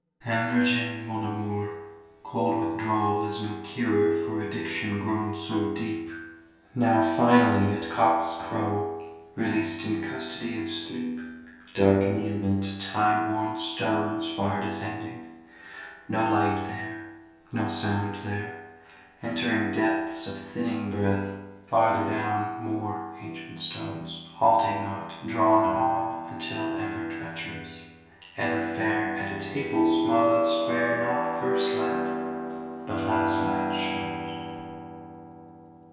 Processing: random stereo + KS = 363/305, F=960, then raise F to 1023